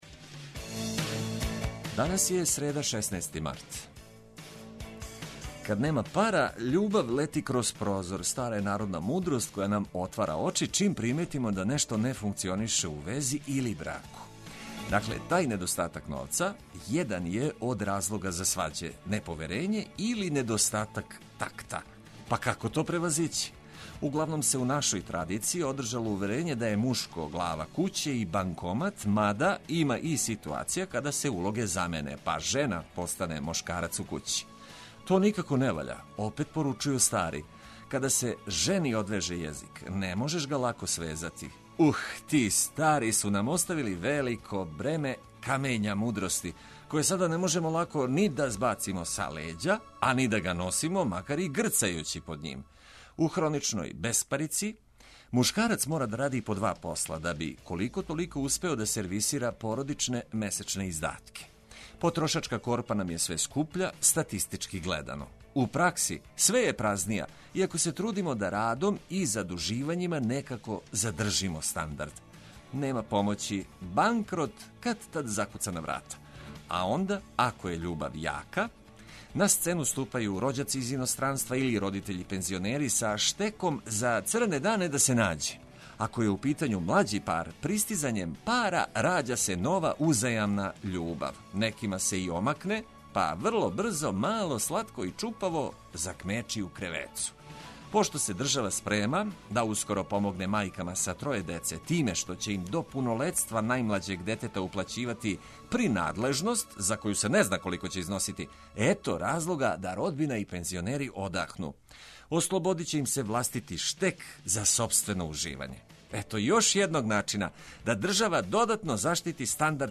Само важне информације и музика за буђење и лакши почетак дана.